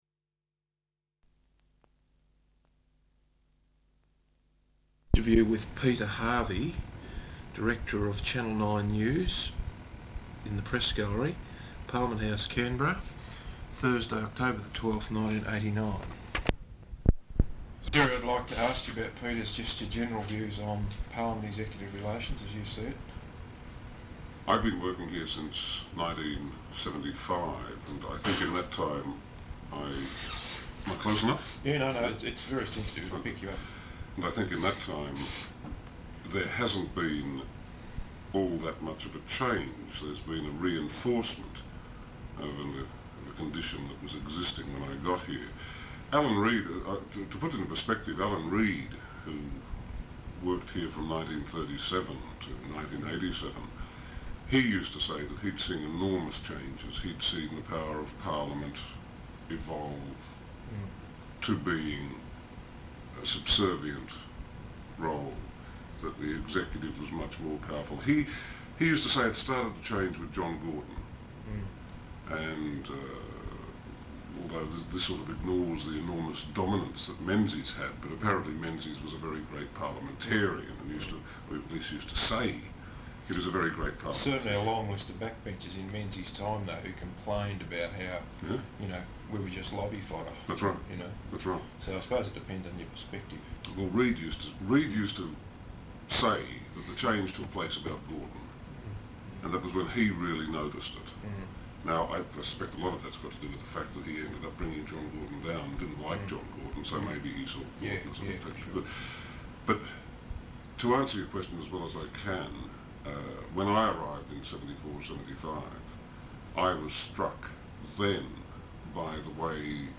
Interview with Peter Harvey, Director of Channel Nine News, in the Press Gallery, Parliament House, Canberra, Thursday 12 October, 1989.